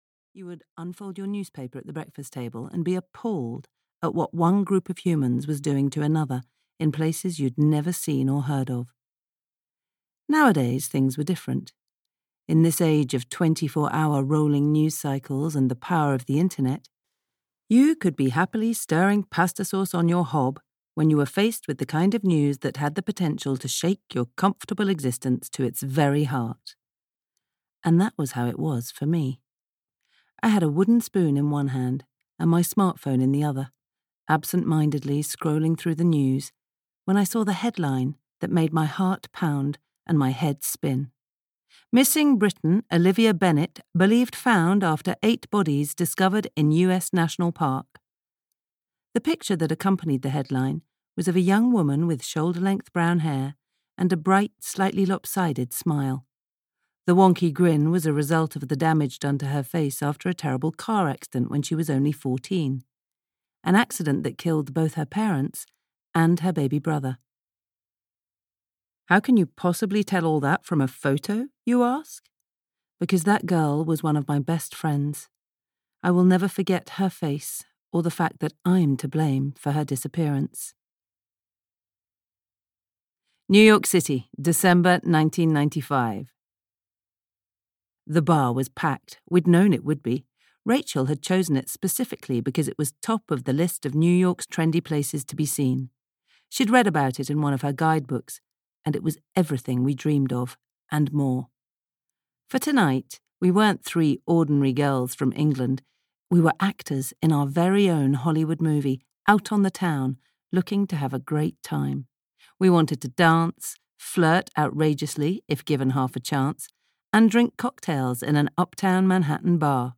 The City of Second Chances (EN) audiokniha
Ukázka z knihy